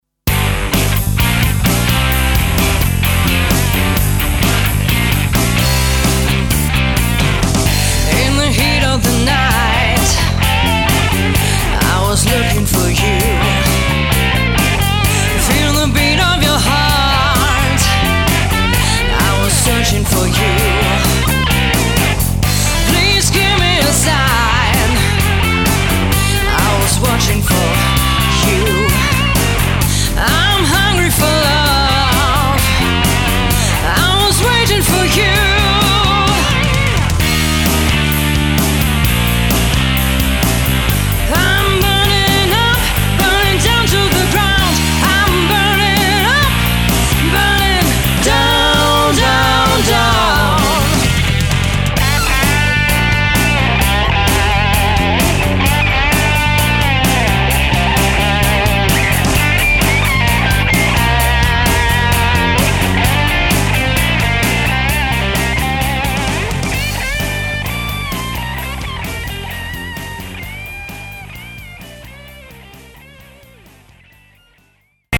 Anspruchsvolle Cover-Songs